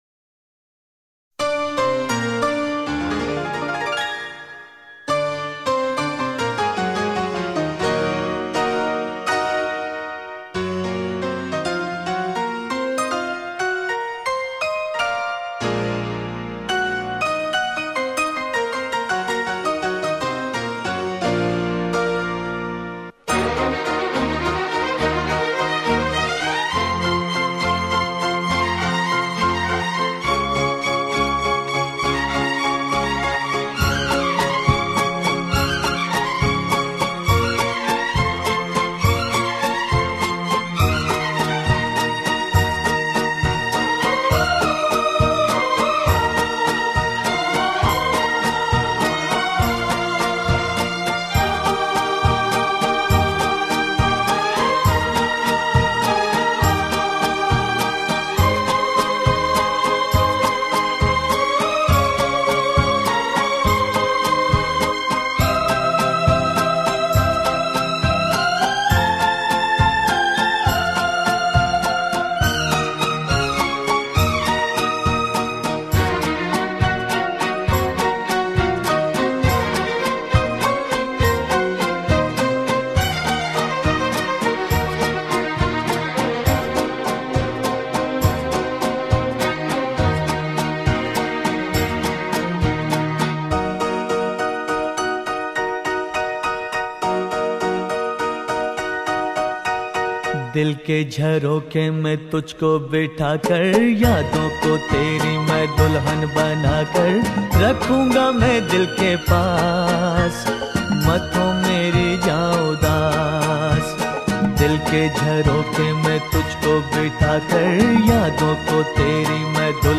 Mood : Sad